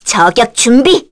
Rodina-Vox_Skill4_kr.wav